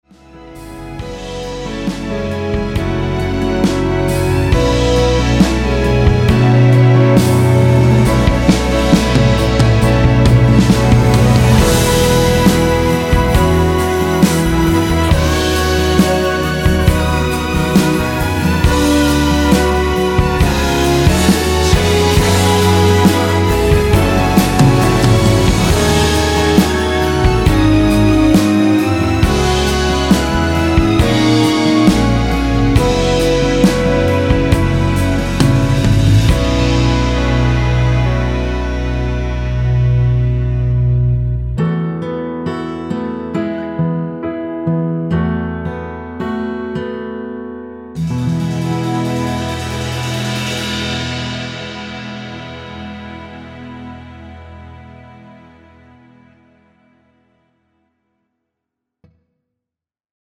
이곡은 코러스가 마지막 한부분만 나와서 그부분만 제작이 되었습니다.(미리듣기 확인)
원키 코러스 포함된 MR입니다.
앞부분30초, 뒷부분30초씩 편집해서 올려 드리고 있습니다.